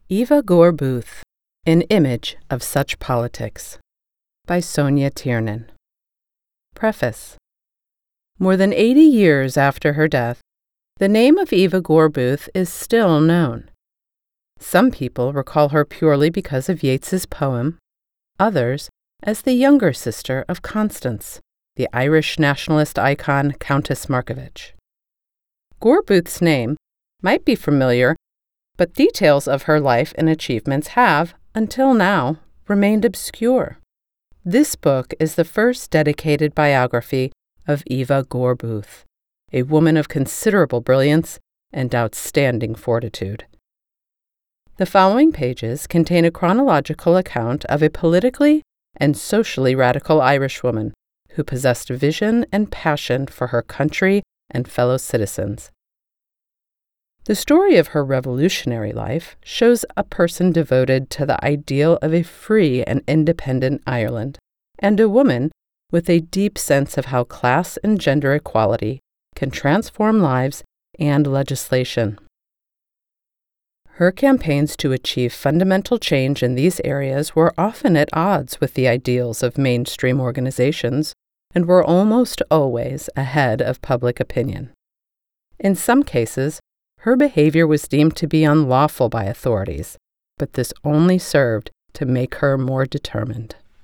Broadcast Quality Voiceover Talent and Certified Audio Engineer
Non-Fiction, Biography
Working from my broadcast-quality home studio is not just my profession—it’s my joy.